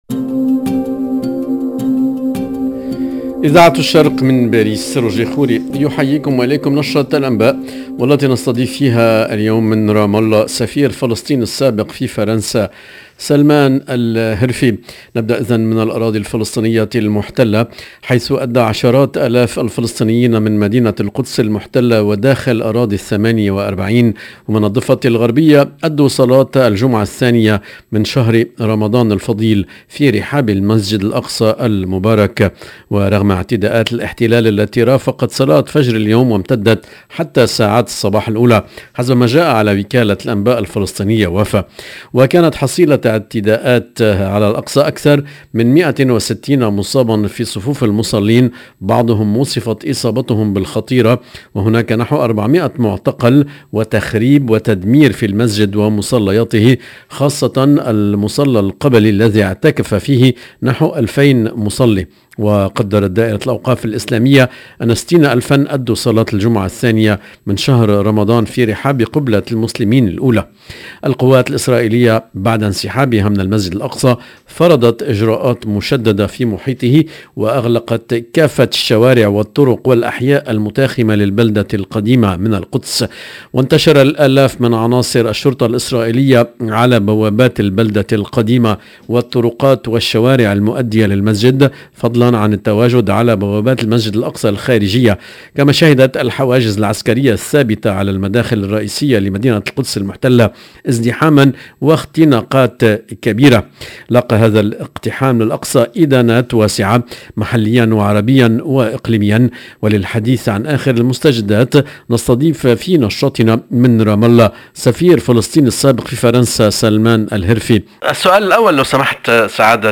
EDITION DU JOURNAL DU SOIR EN LANGUE ARABE DU 15/4/2022